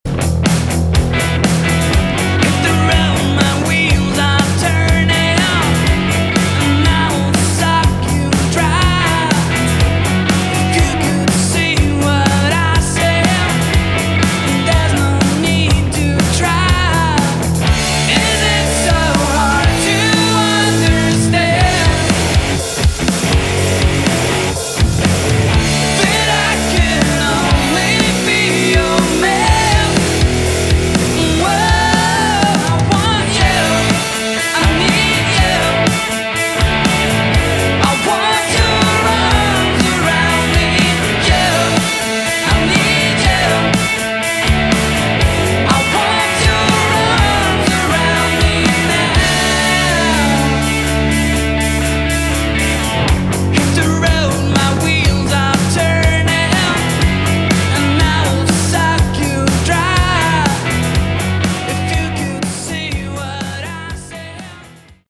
Category: Melodic Rock
Bass
Lead Vocals, Guitar
Drums
Keyboards
Summer 2007 Demo